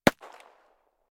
Godot-Multiplayer/weapon_gun_1911_A_41.ogg at 9791aa763d5629f28cf973d1bbd1ec50978ecab8